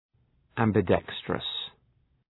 Shkrimi fonetik{,æmbı’dekstrəs}
ambidextrous.mp3